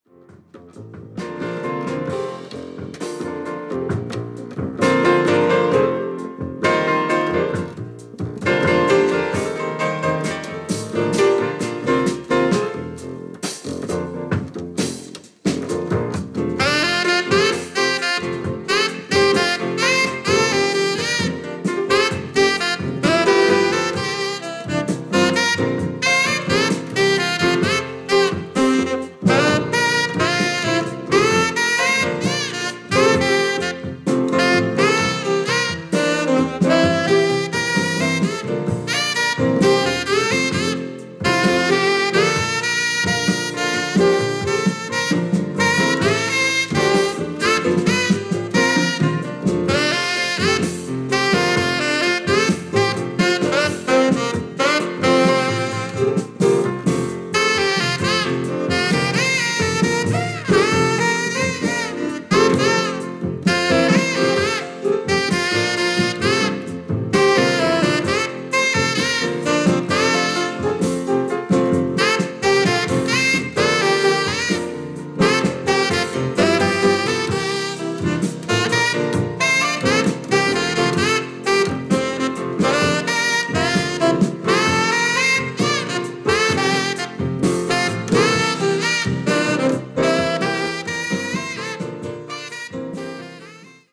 Piano
Bass
Drums
Saxophone